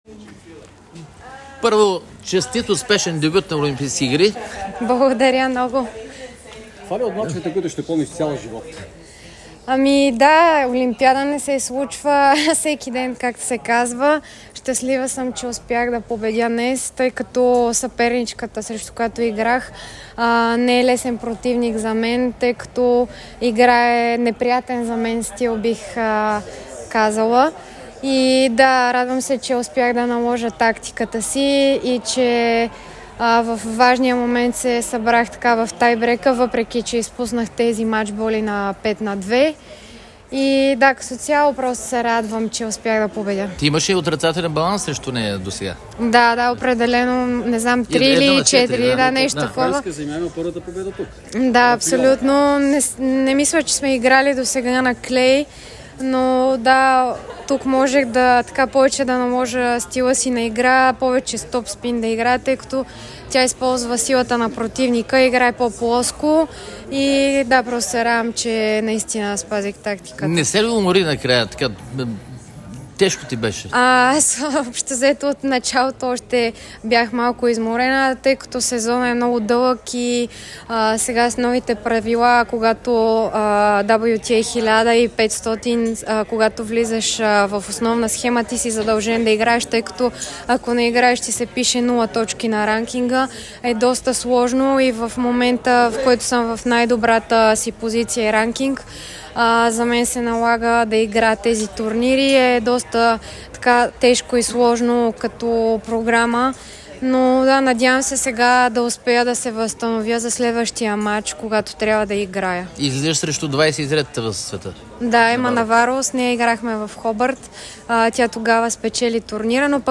Радвам се, че победих“, каза Томова пред репортери.